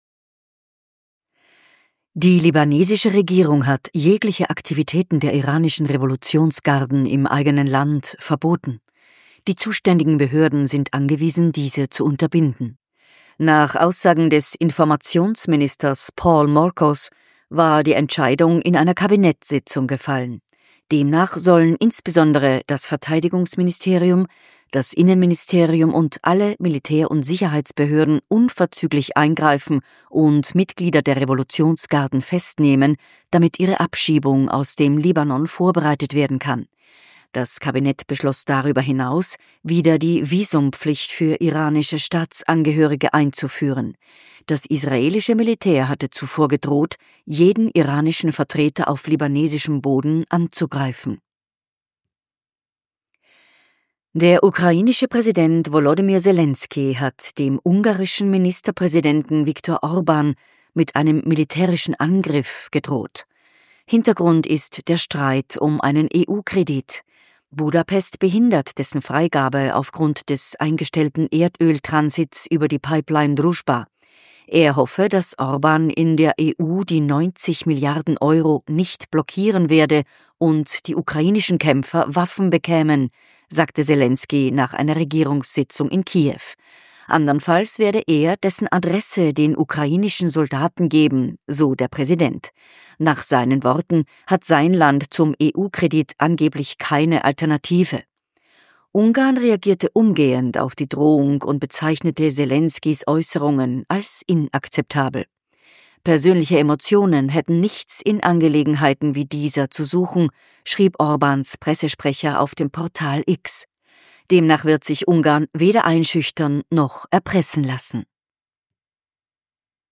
Kontrafunk aktuell 6.3.2026 – Nachrichten vom 6.3.2026